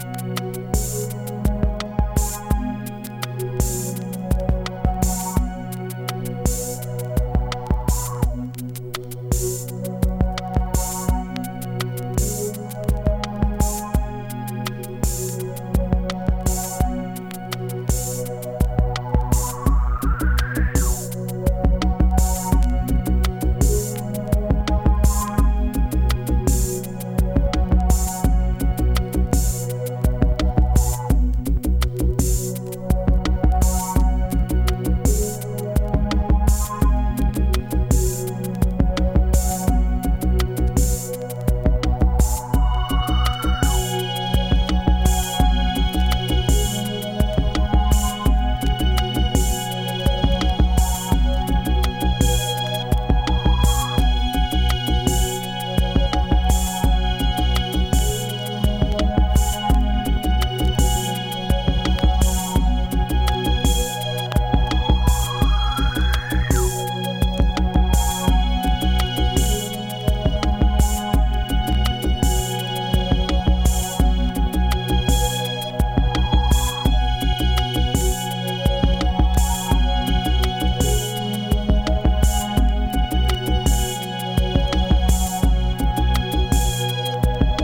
淡々とハメてくるウォーミーなDubby Tech A-2オススメ。
リラクシンなダウンテンポ～IDMトラック